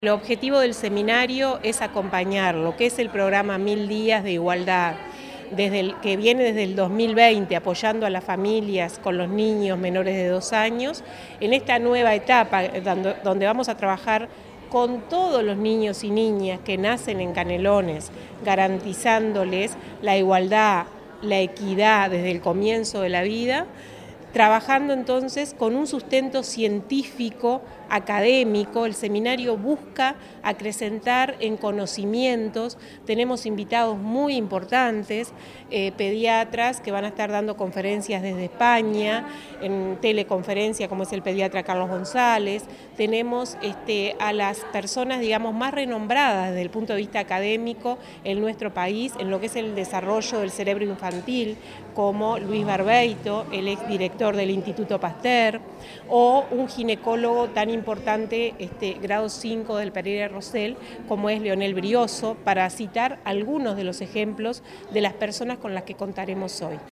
gabriela_garrido_directora_general_de_desarrollo_humano.mp3